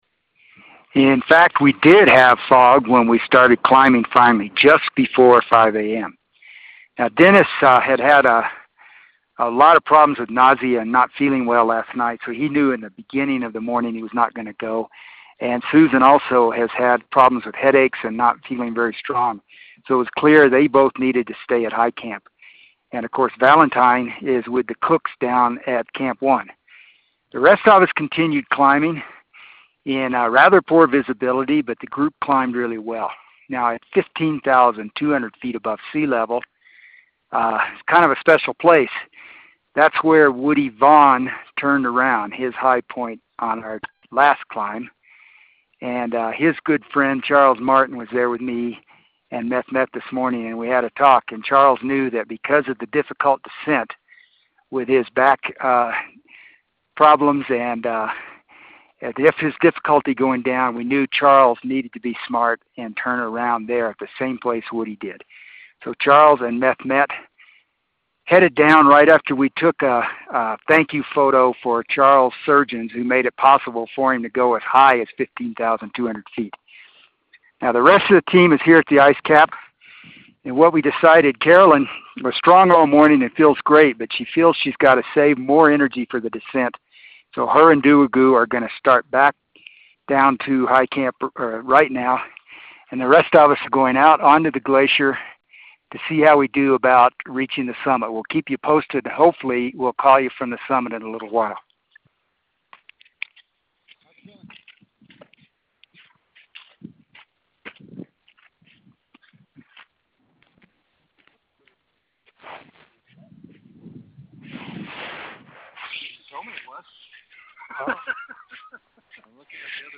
At 15,200 Feet